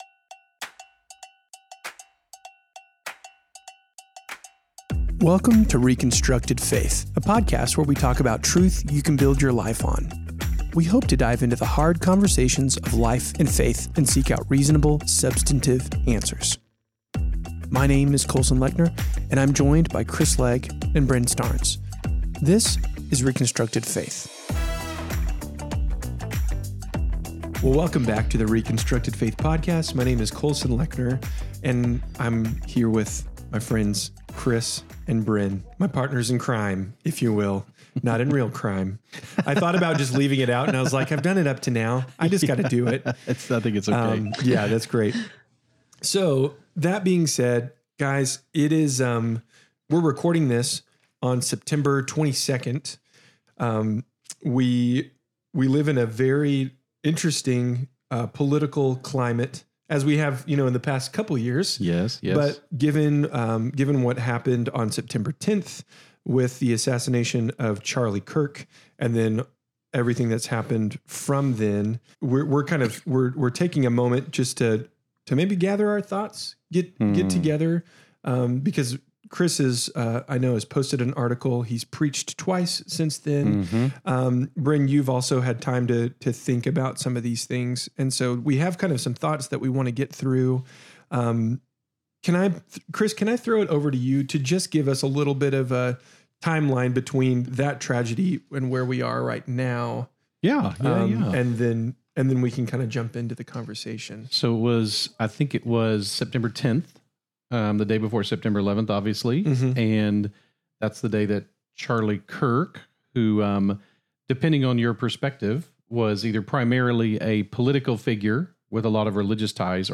125. Strong Faith When The World is Shaken – A Conversation on the Charlie Kirk Assassination